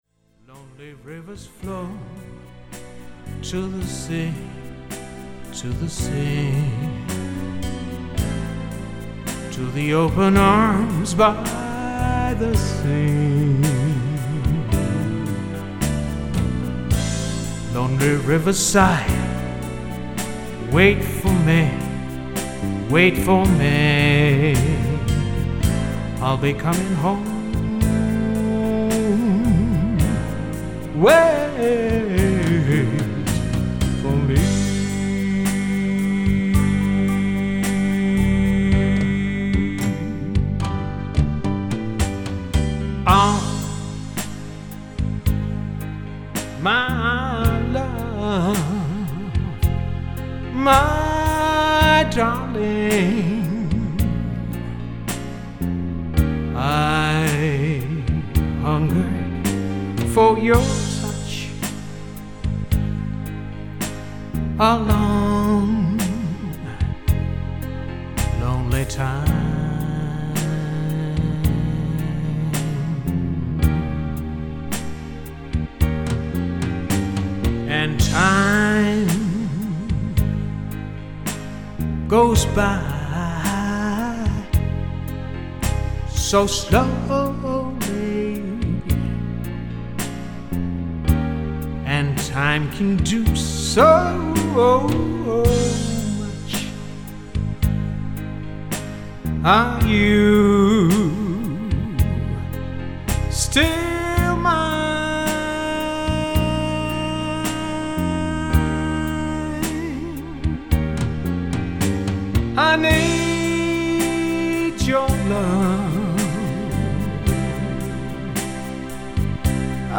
R & B / Motown / Soul